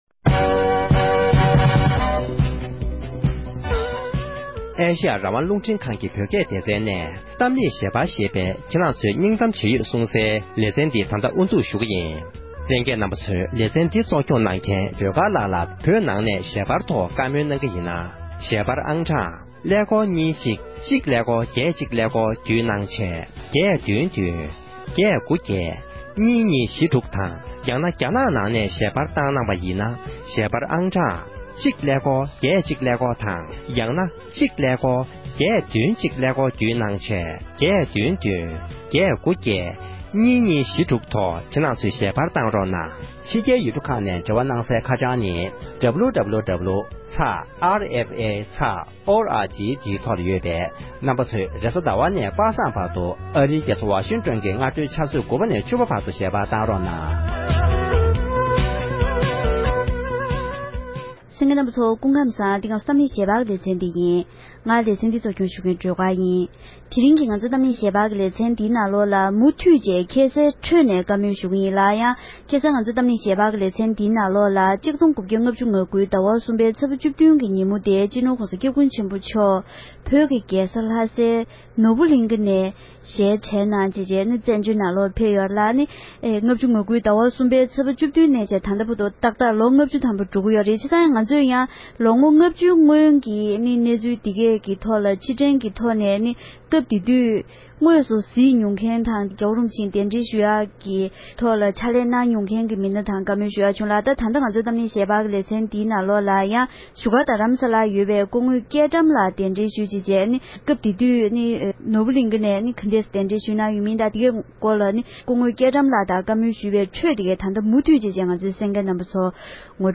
ལོ་ངོ་ལྔ་བཅུའི་སྔོན་༸གོང་ས་མཆོག་བོད་ནས་གདན་འདྲེན་ཞུ་མཁན་གྱི་སྣ་ཁག་ཅིག་གི་ལྷན་བགྲོ་གླེང་ཞུས་པ།